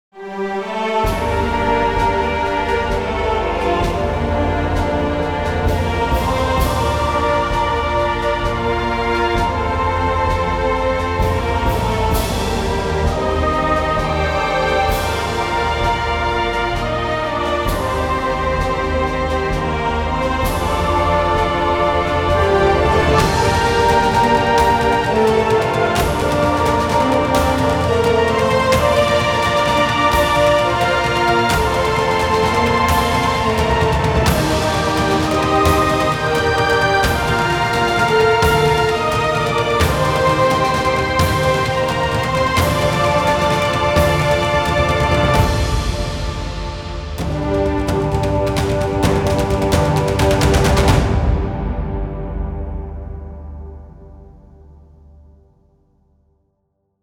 Some trailer or main menu theme. Almost Epic.